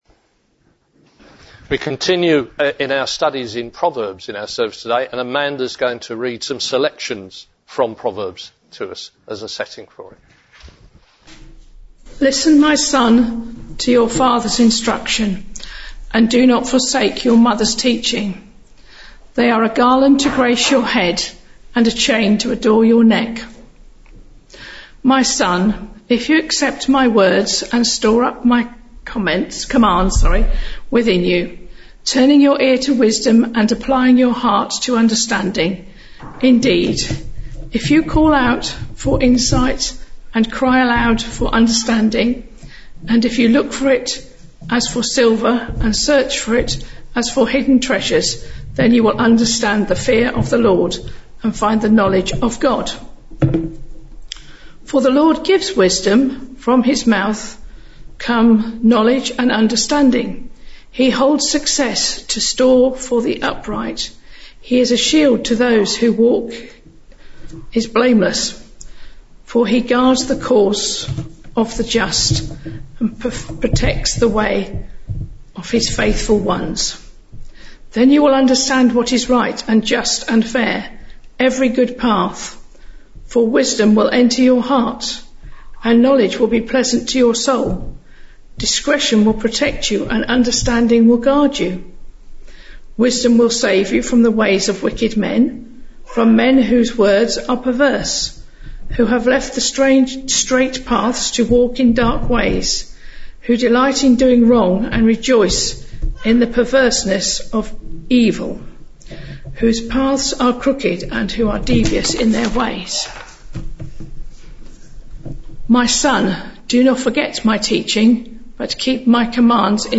Bluntisham Baptist Church Sermons